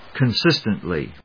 音節con・sís・tent・ly 発音記号・読み方
/kʌˈnsɪstʌntli(米国英語), kʌˈnsɪstʌntli:(英国英語)/